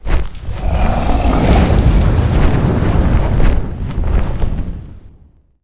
CosmicRageSounds / wav / general / combat / creatures / dragon / plume.wav